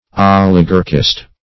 Oligarchist \Ol"i*gar`chist\, n. An advocate or supporter of oligarchy.